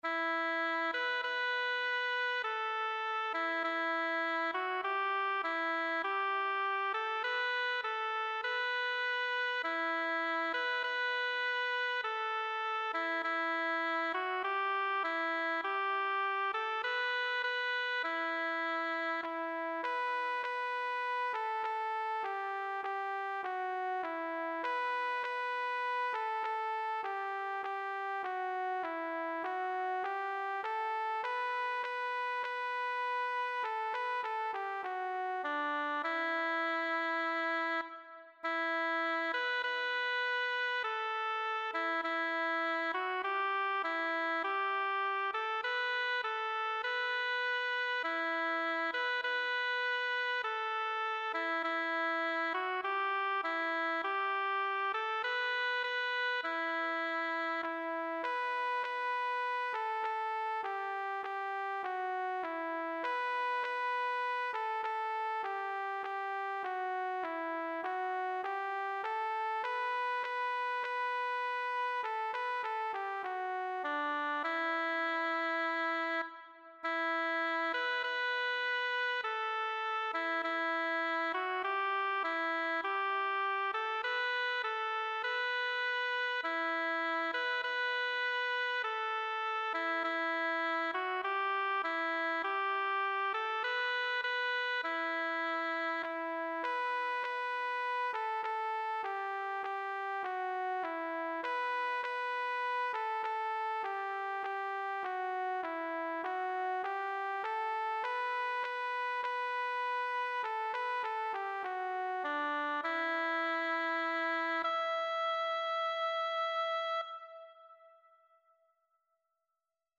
Abraham-y-El-Yo-Soy-SolM.mp3